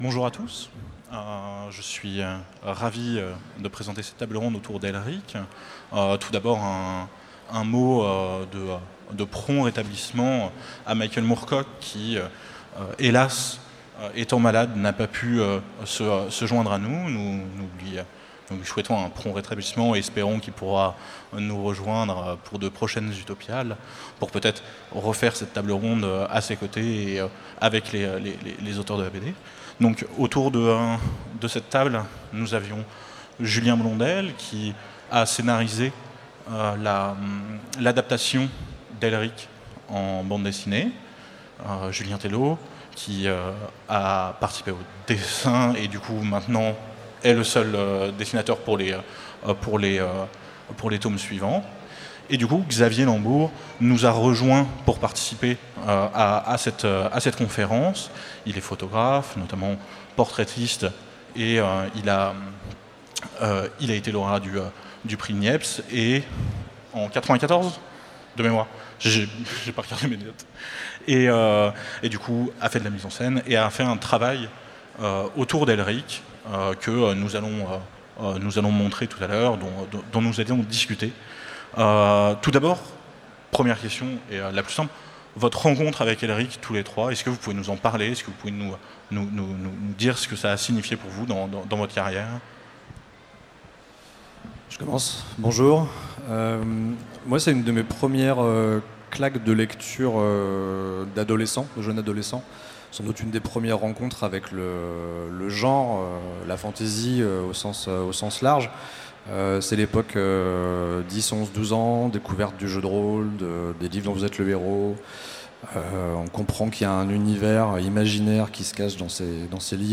Utopiales 2017 : Conférence Elric en BD, adapter un mythe